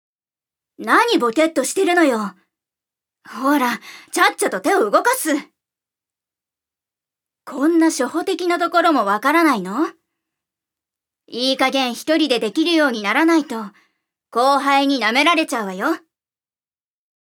預かり：女性
音声サンプル
セリフ３